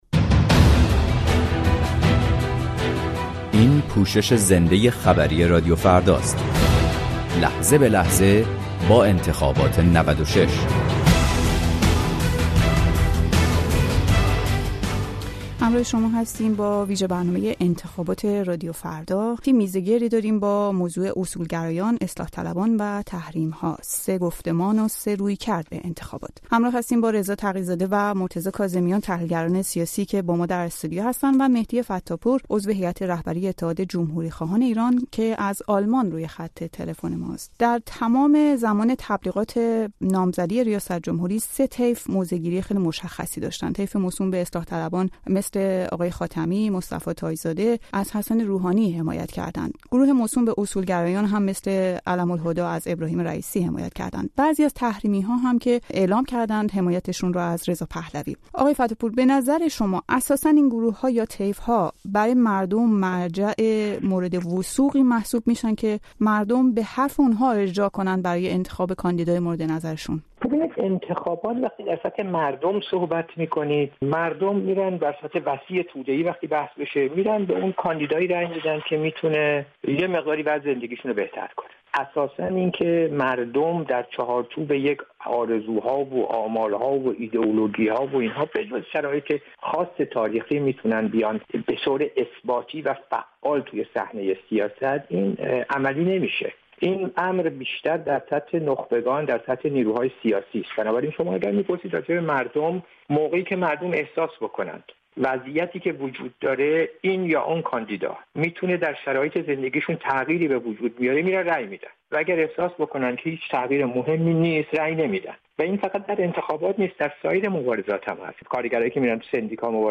میزگردی
در استودیوی رادیو فردا در پراگ